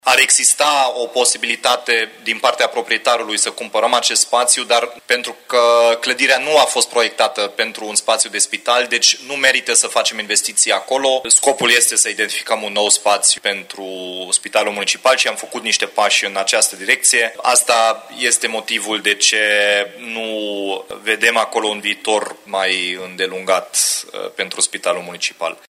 În Consiliul Local a fost ridicată și problema cumpărării clădirii, dar primarul Dominic Fritz a spus că, având în vedere că se dorește construirea unui nou spital Municipal, achiziționarea imobilului nu este oportună: